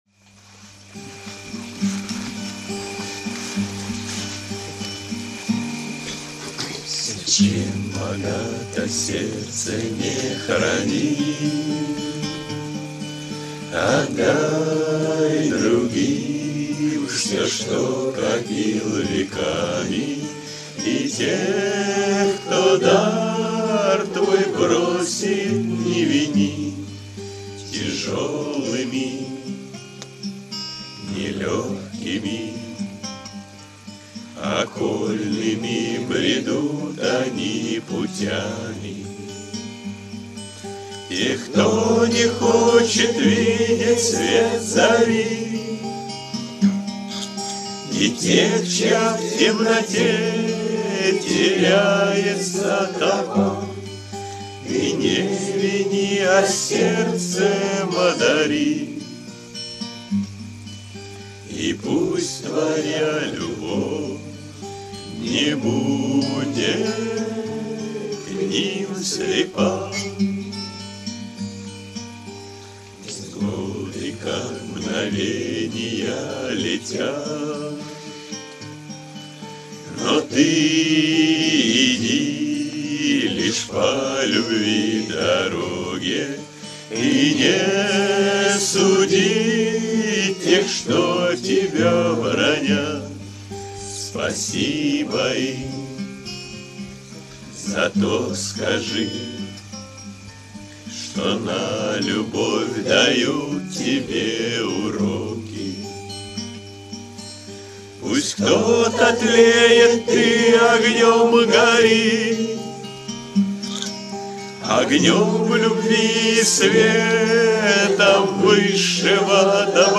кавер-версия на мотив украинской песни
акапелла